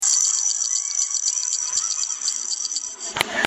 リオパラリンピックの音の鳴る金メダル
メダルの中が空洞になっていて、小さな鉄球（金メダルには２８個・銀メダルには２０個・銅メダルには１６個）が入っていて、振ると鈴のような音が鳴る仕組みになっているのです。音の大きさで金・銀・銅を区別できるようになっていて、金メダルが一番大きい音が出るようなっています。
→ここをクリックすると、リオパラリンピック金メダルの音が鳴ります